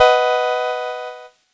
Cheese Chord 05-B2.wav